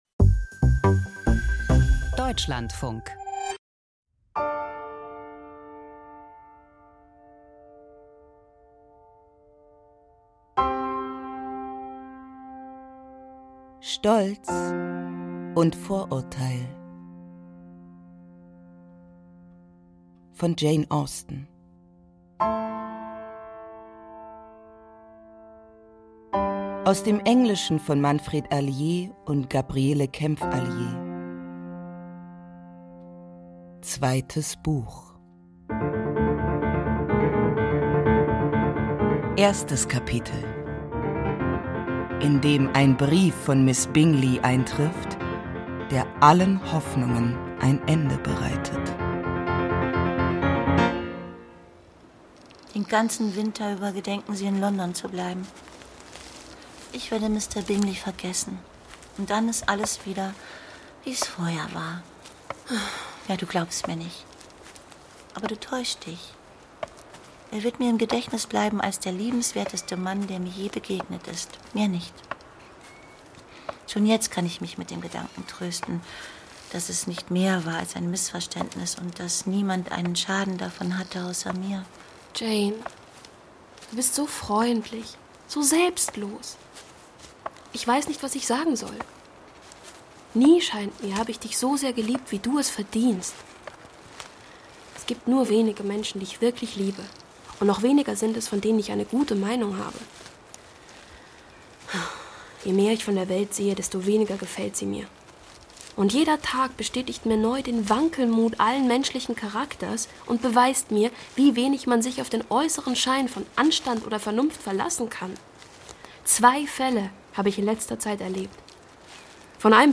Der Klassiker der Weltliteratur als dreiteiliges Hörspiel.